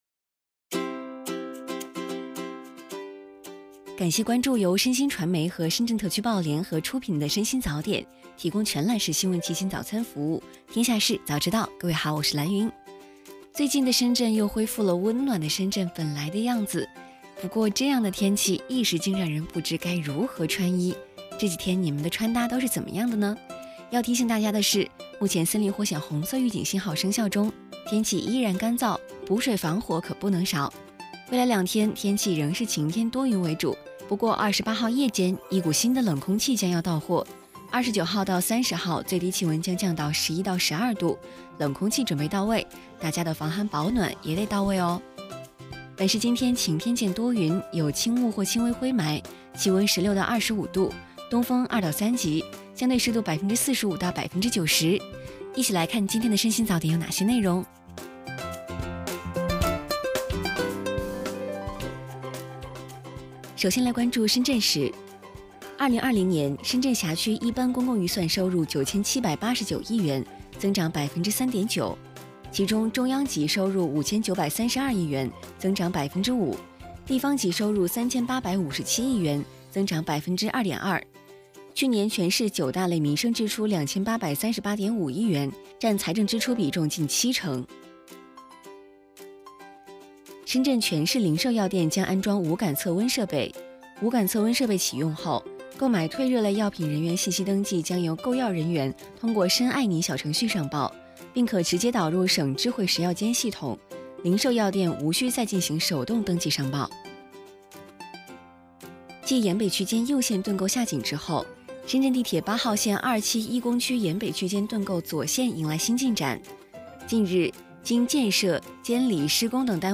配音